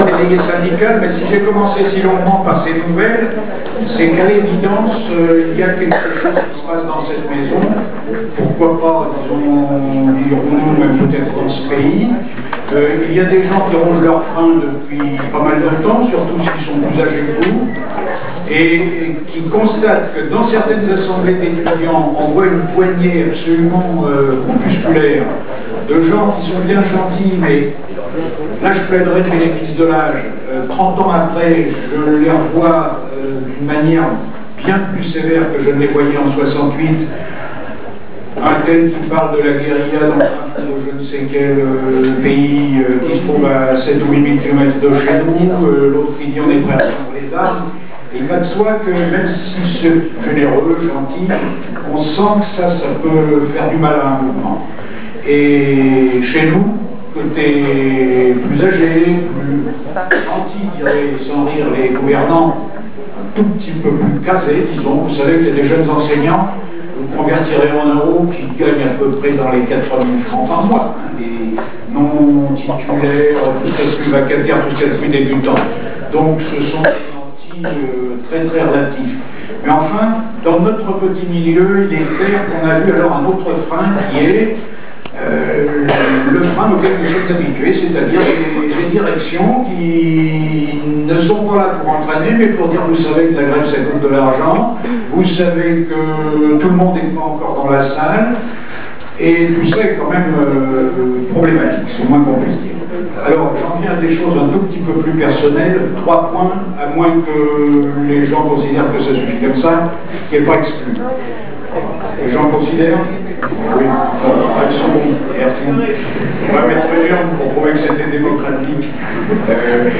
Comme pour les interventions en réunion d’info des personnels, voici celles de ce mercredi, en amphi de débats avec les étudiants.
Mais ensuite, l’intervention est complète, éventuellement coupée en plusieurs prises, pendant les applaudissements.